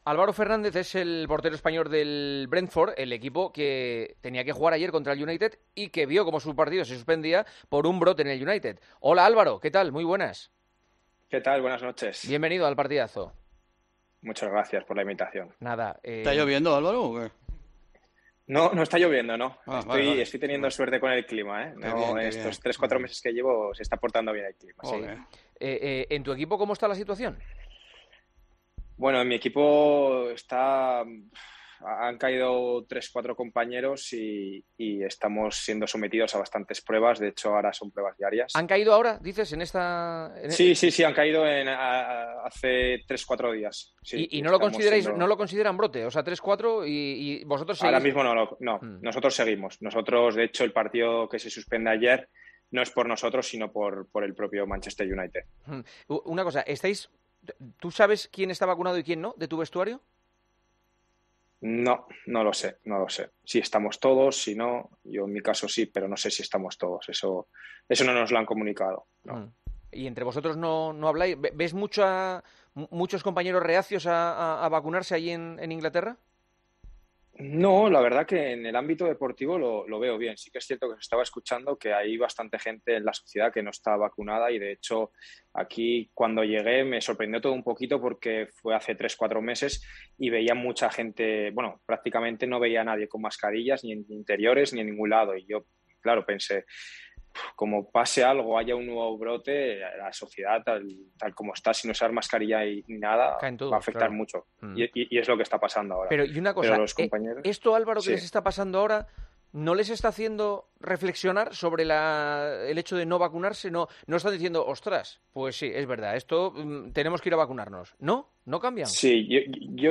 AUDIO: Hablamos con el portero del Brentford sobre la gran escalada de casos por COVID que están saliendo en la Premier League.